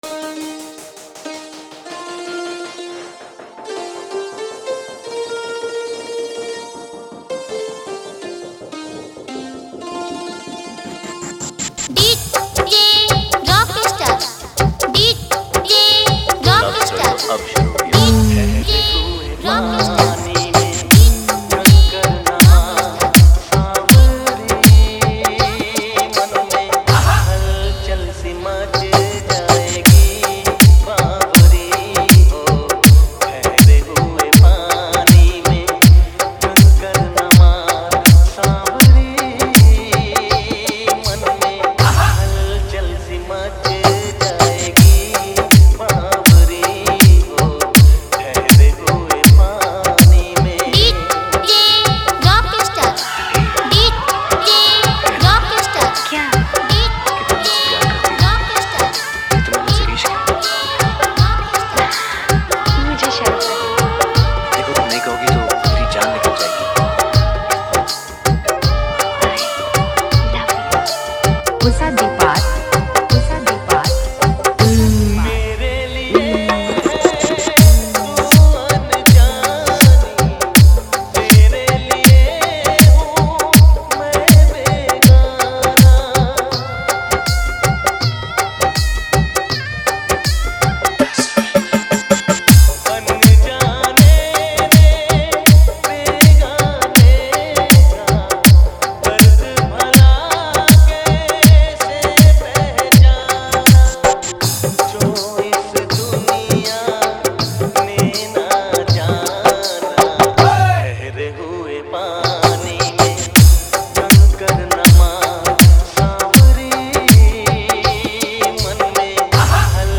Category:  Love Dj Remix